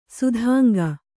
♪ sudhānga